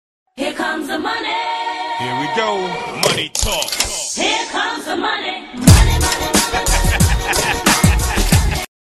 twitch-donation-notification-here-come-the-money.mp3